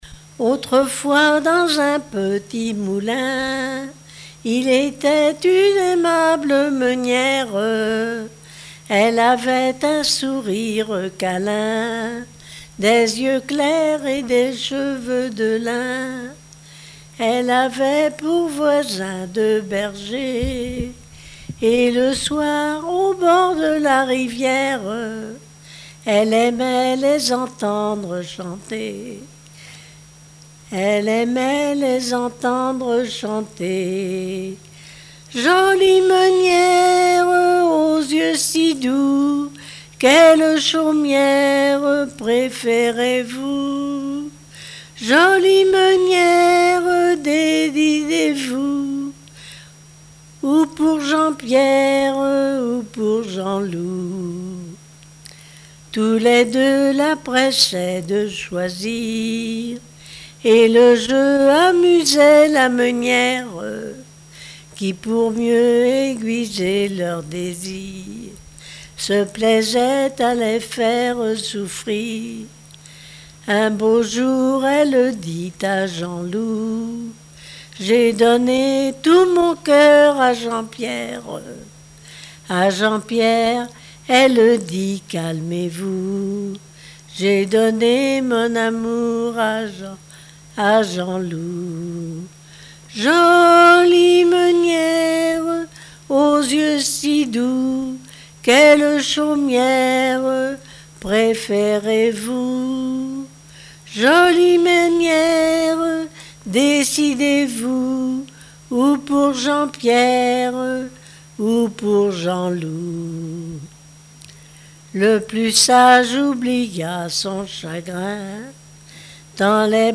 Genre strophique
Répertoire de chansons populaires et traditionnelles
Pièce musicale inédite